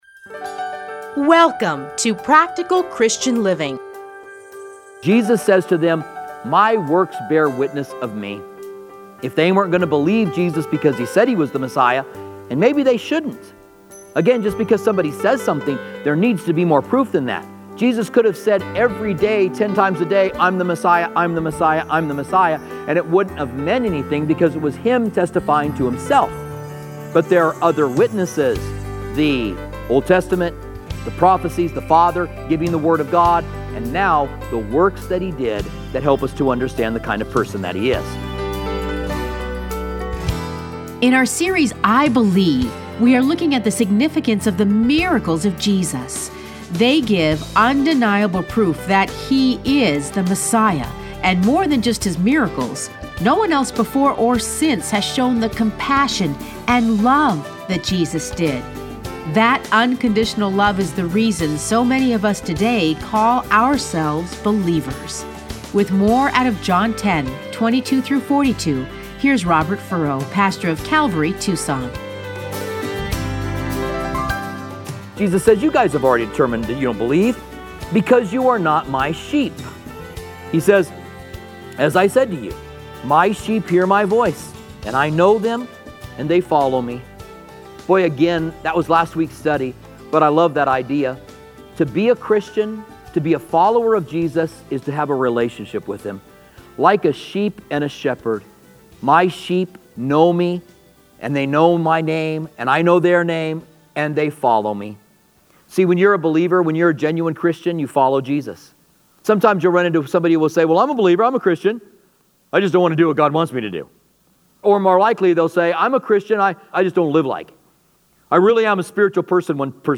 Listen to a teaching from John 10:22-42.